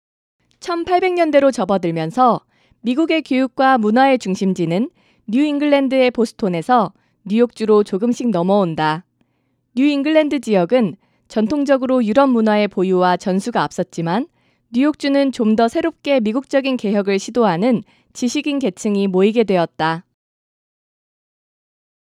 VoiceOver artist living and working in Los Angeles / Orange County fluent in Korean & English equipment used: - Behringer FCA610 - RME BabyFace Pro - ElectroVoice RE27N/D mic - Logic Pro X for recording
Mature Adult, Adult, Young Adult Has Own Studio
professional home studio